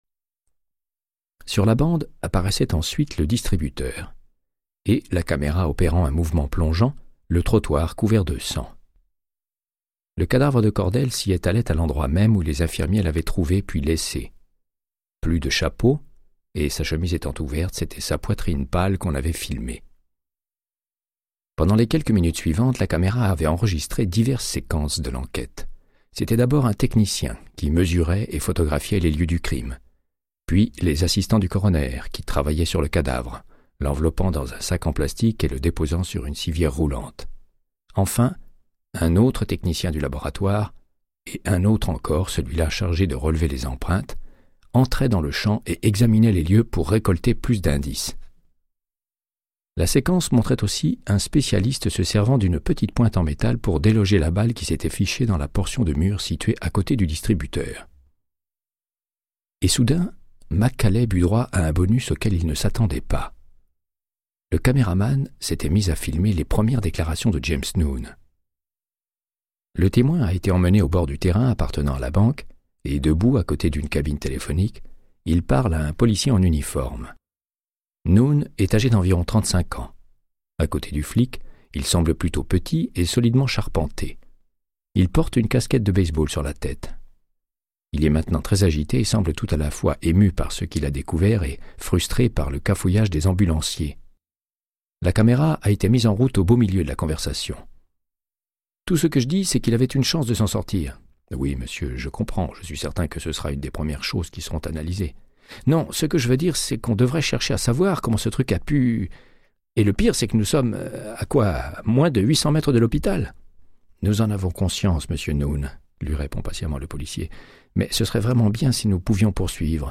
Audiobook = Créance de sang, de Michael Connellly - 36